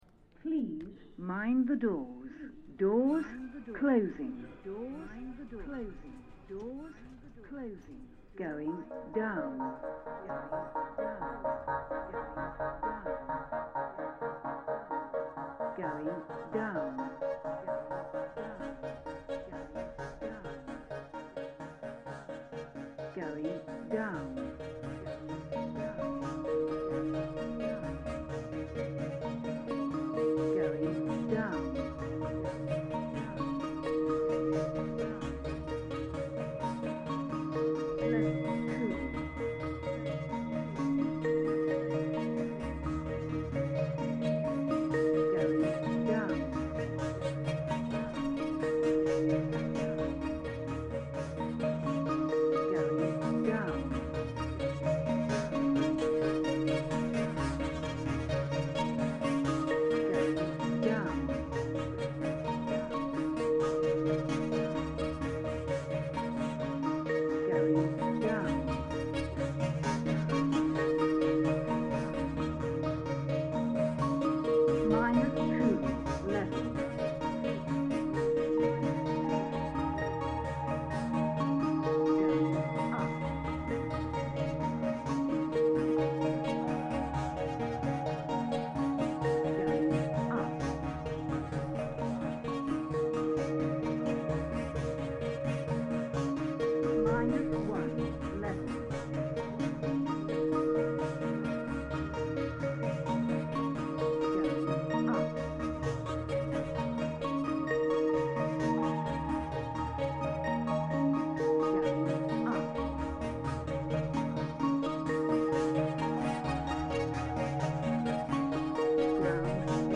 Barbican Centre lifts reimagined